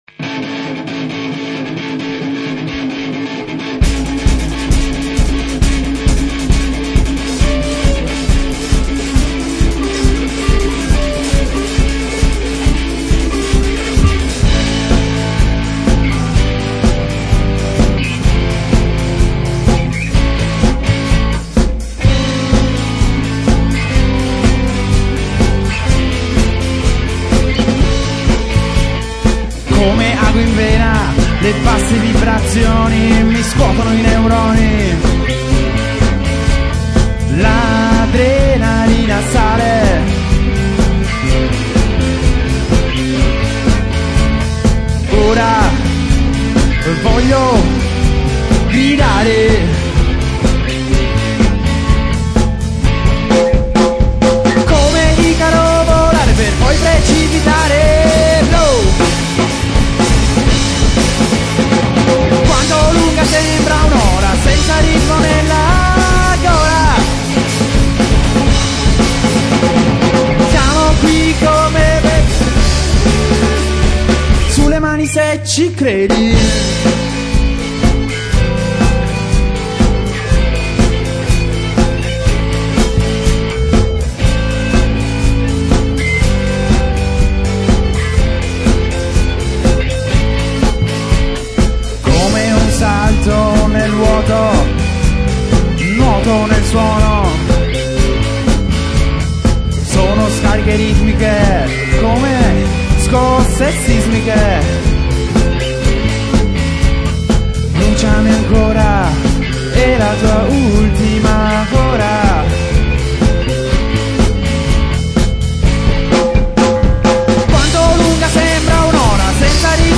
Genere: Rock